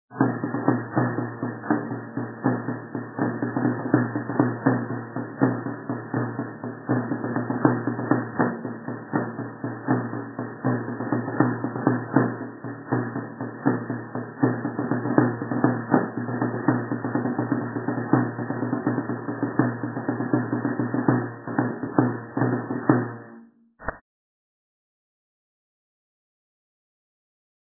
10)  Toque de jota (cuando toca la donzaina)